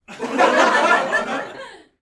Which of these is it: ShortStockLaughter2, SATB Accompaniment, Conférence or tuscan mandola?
ShortStockLaughter2